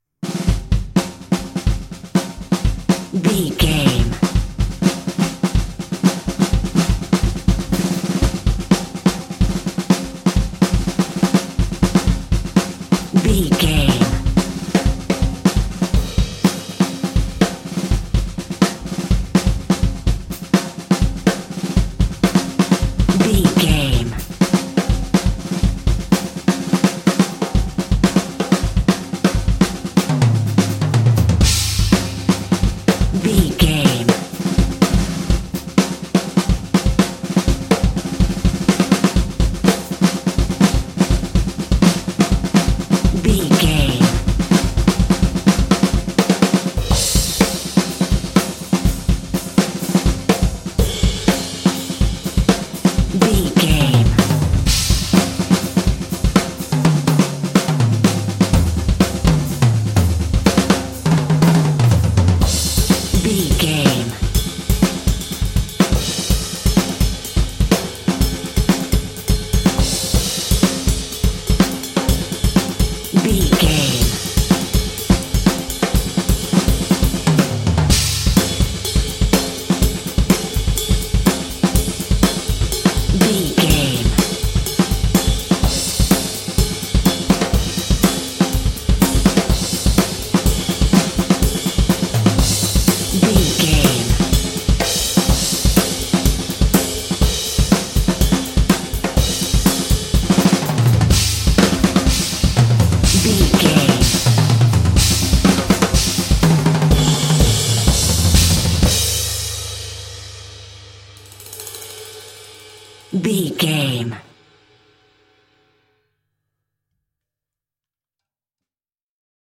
Fast paced
Aeolian/Minor
driving
energetic
drumline
contemporary underscore